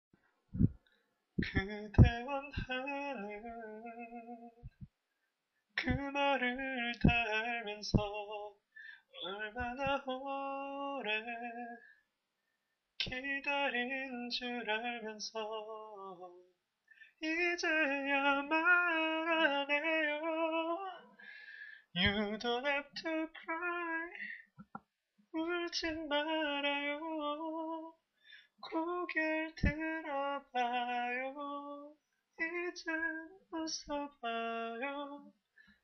고음이 힘드러여